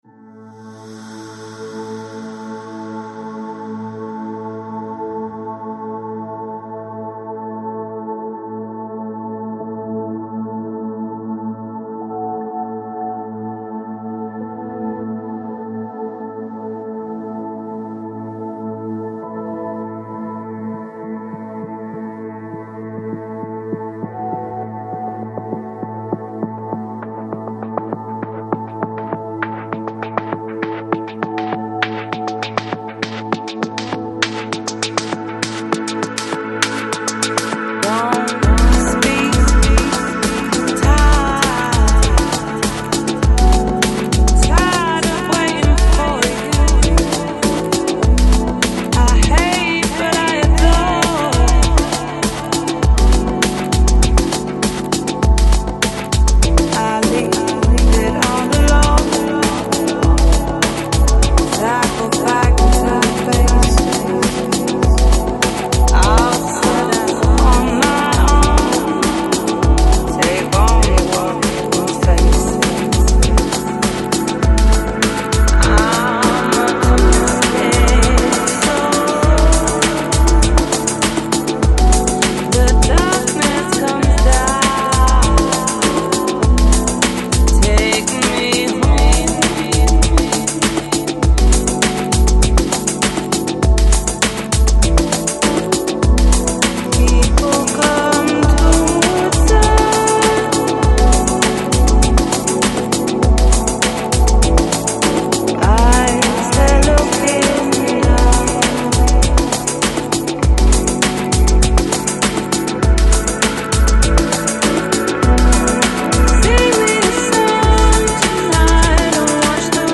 Balearic, Downtempo Издание